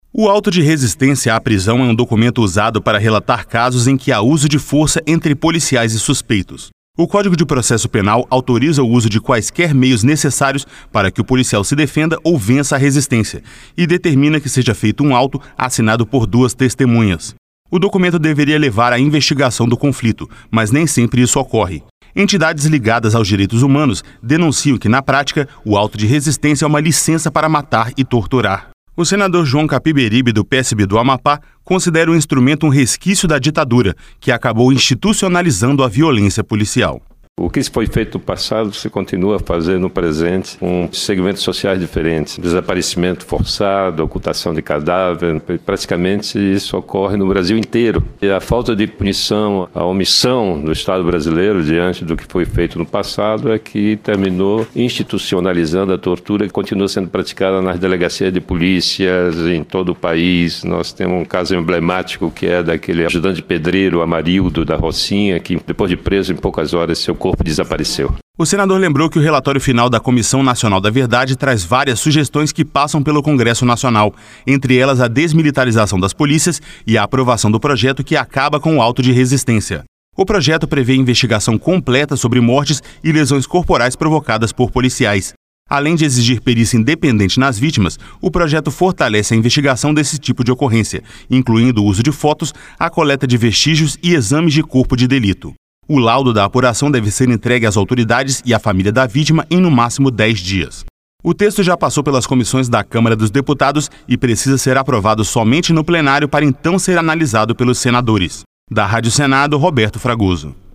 O senador João Capiberibe, do PSB do Amapá, considera o instrumento um resquício da ditadura, que acabou institucionalizando a violência policial.